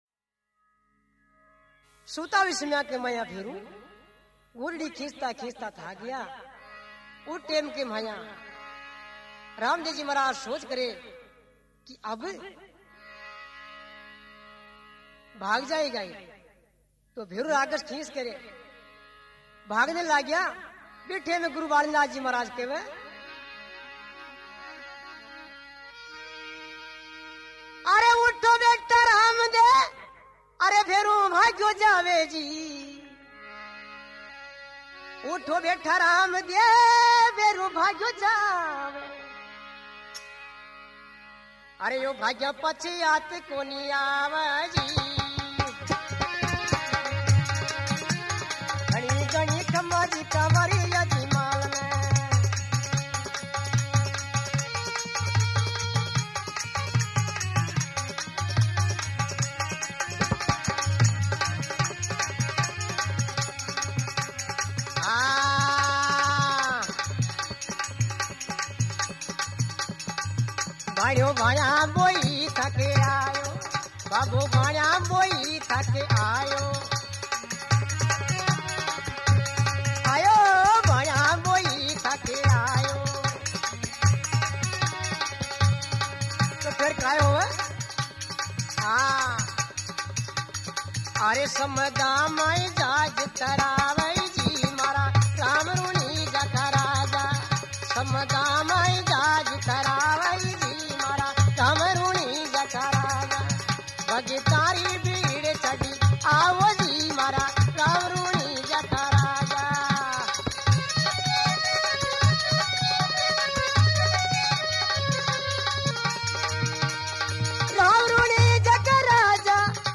Rajasthani Songs
Baba Ramdev Bhajan